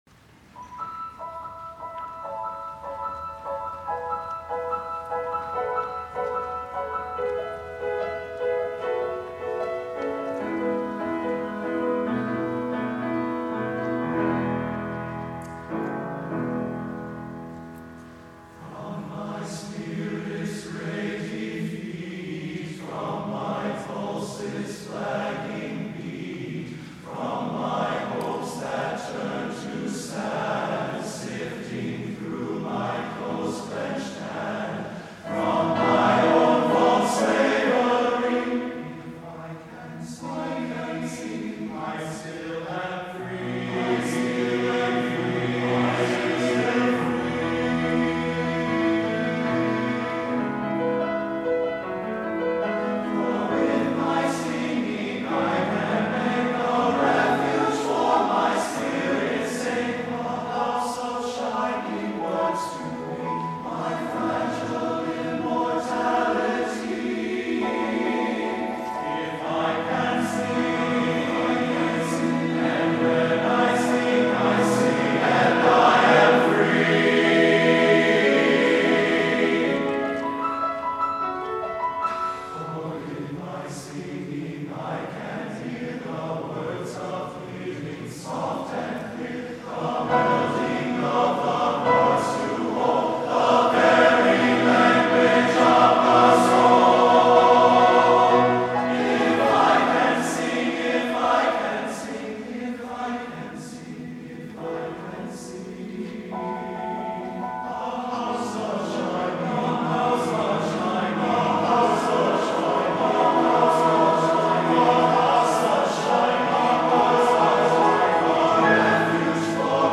for TTBB Chorus and Piano (2016)
[The shining image lead to a shimmering accompaniment.]